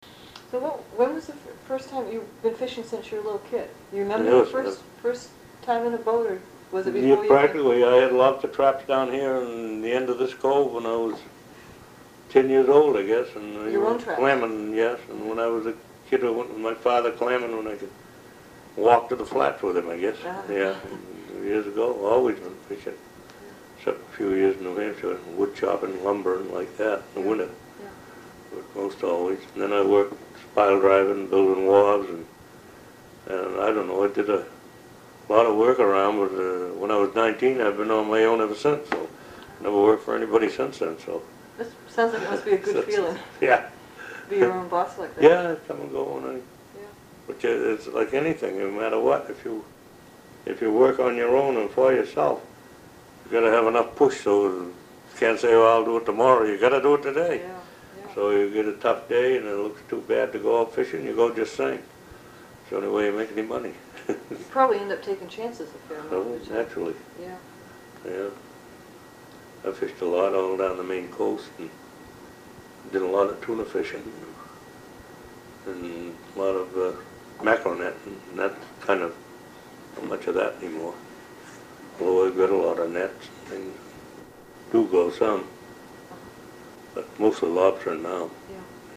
Oral History Overview
In 2003, most of the original sound cassettes were converted to compact discs by the Sawyer Free Library.